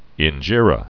(ĭn-jîrə)